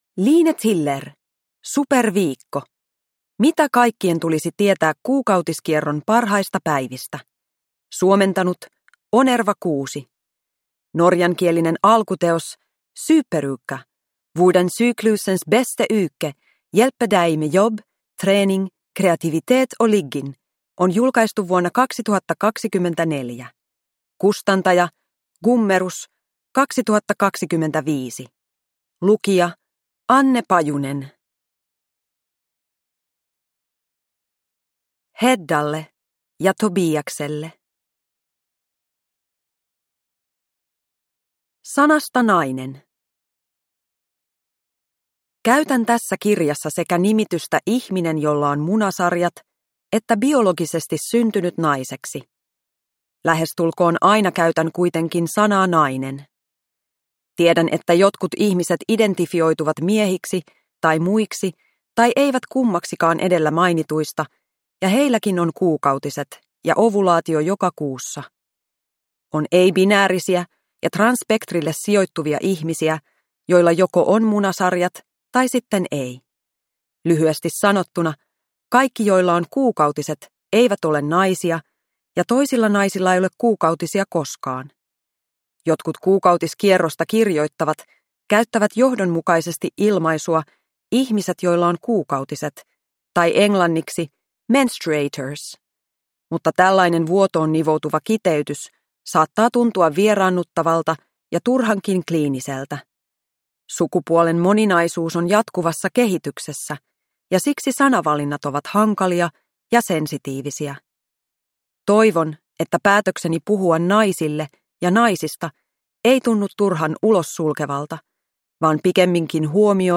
Superviikko – Ljudbok